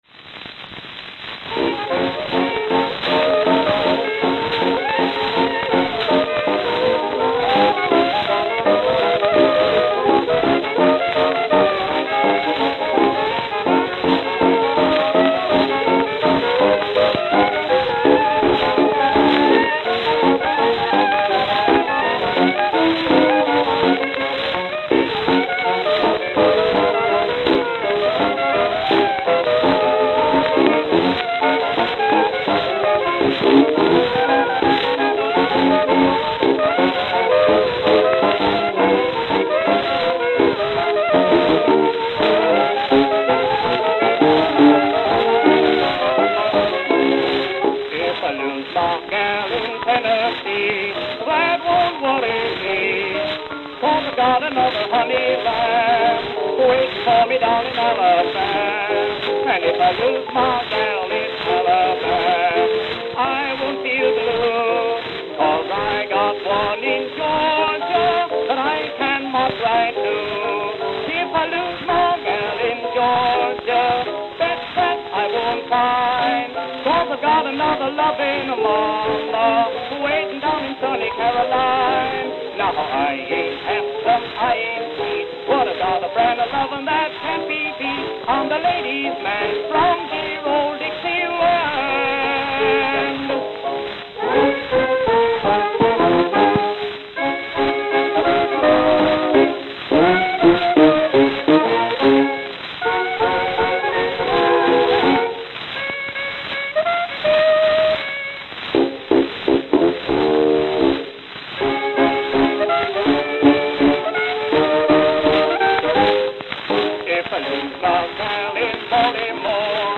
Note: Very worn.